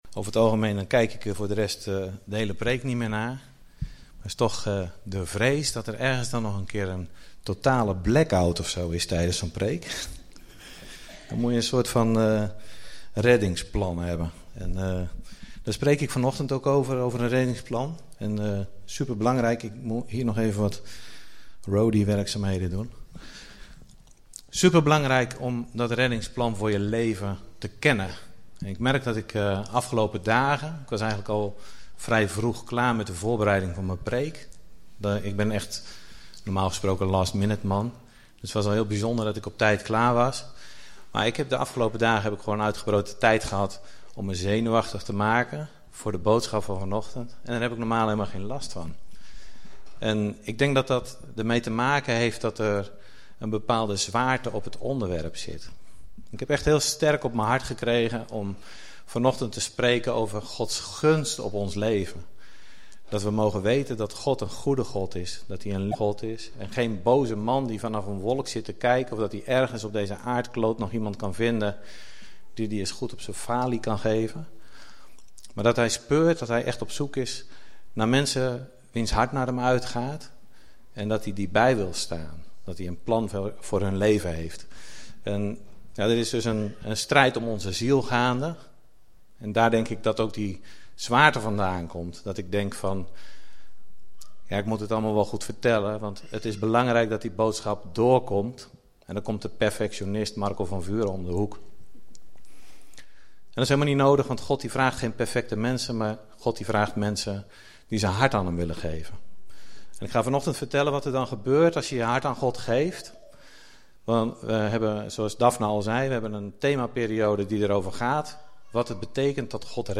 In deze podcast staan alle opgenomen toespraken van Leef! Zutphen vanaf 3 februari 2008 t/m nu.